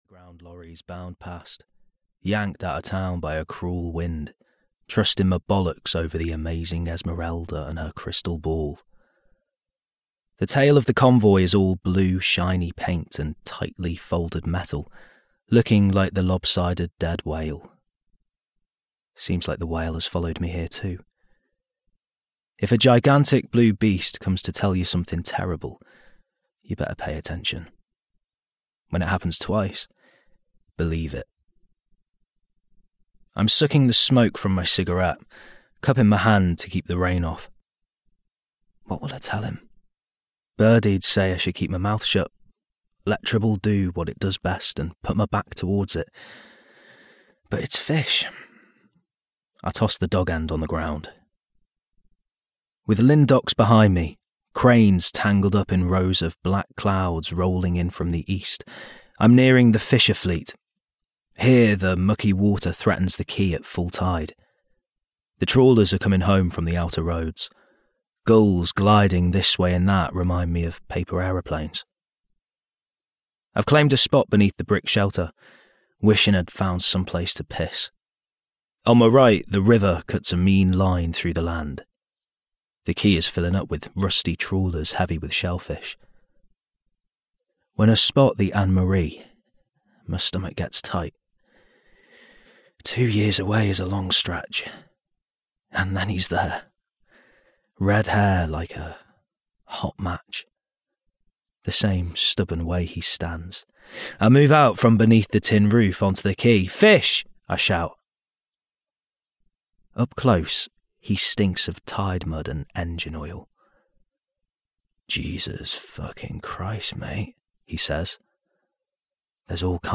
The Whale Tattoo (EN) audiokniha
Ukázka z knihy